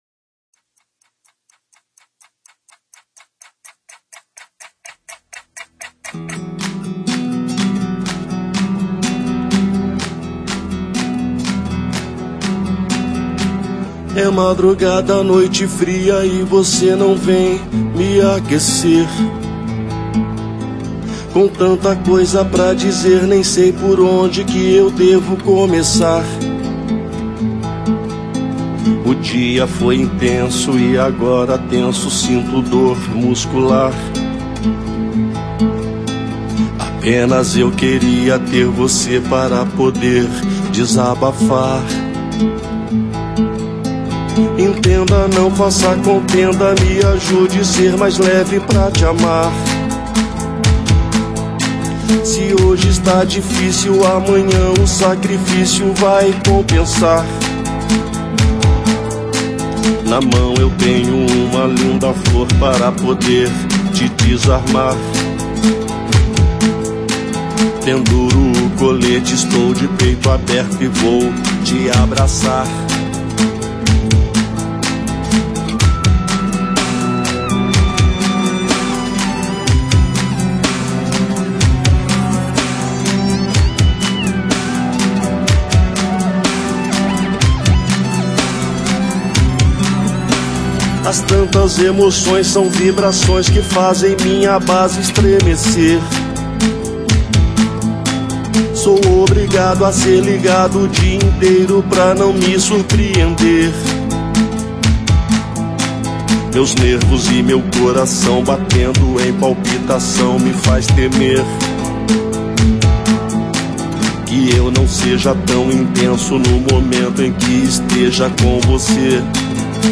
EstiloAlternativo